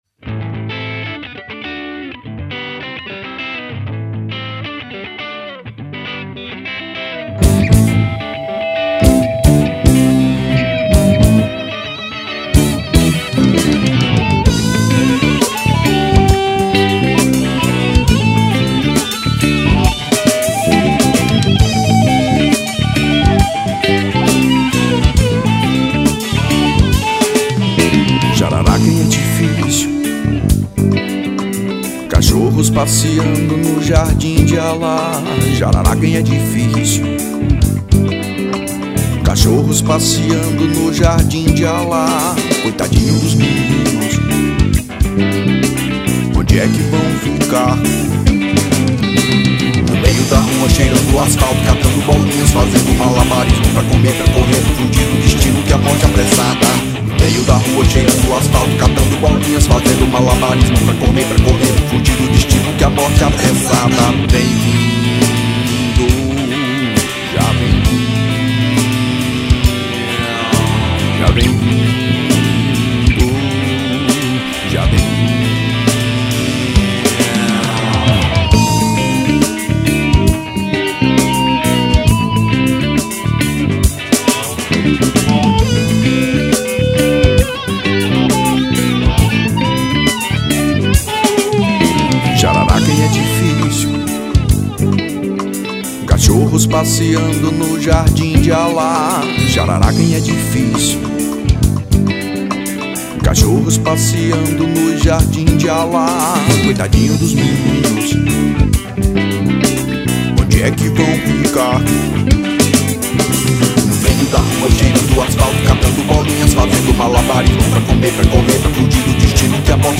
1952   03:20:00   Faixa:     Rock Nacional